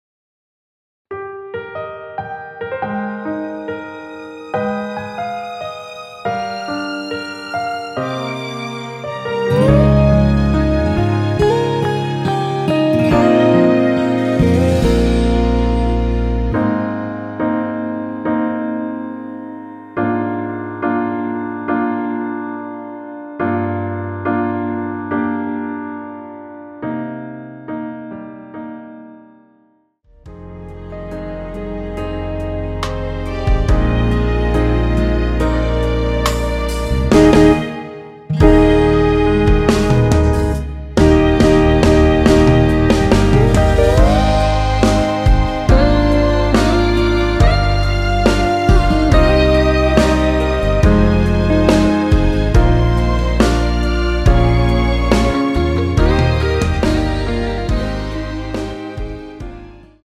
원키(1절앞+후렴)으로 진행되는 MR입니다.
Eb
앞부분30초, 뒷부분30초씩 편집해서 올려 드리고 있습니다.